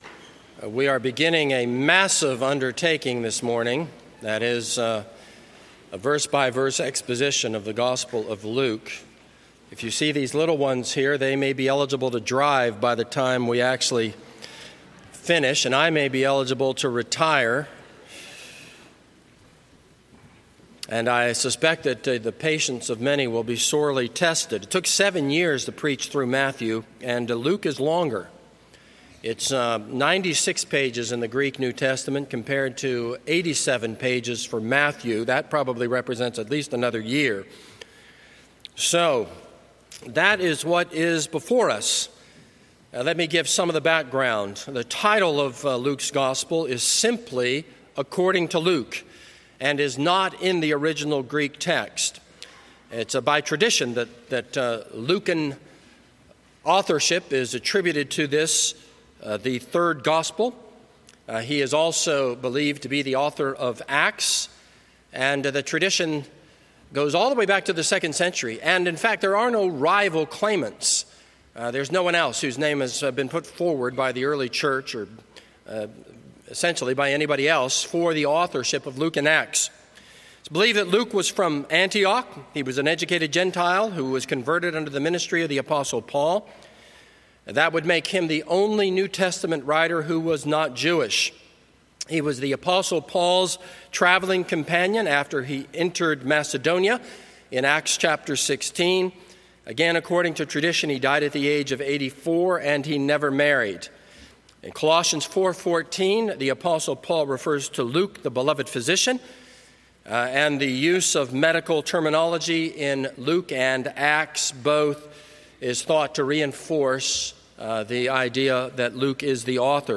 This is a sermon on Luke 1:1-4.